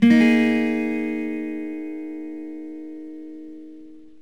Dssus4.mp3